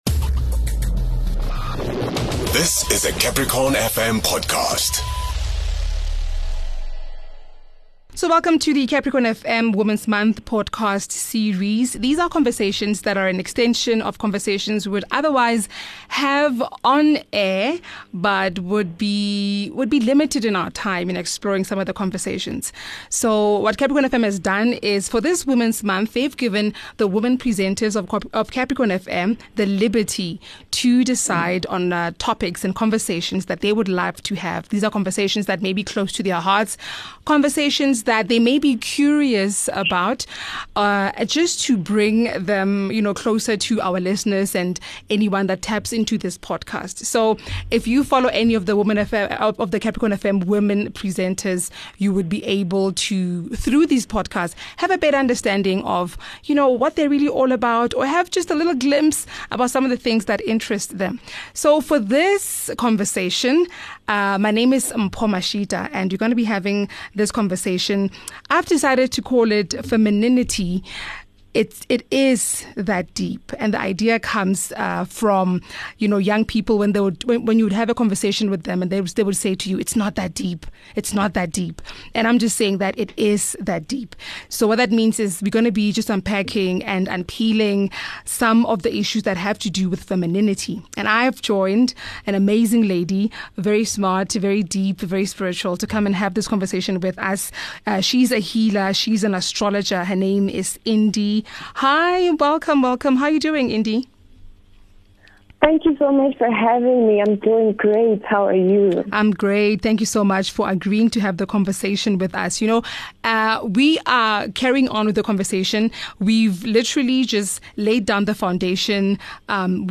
The Capricorn FM Women's Month Podcast Series are extended conversations hosted by Capricorn FM's female Presenters.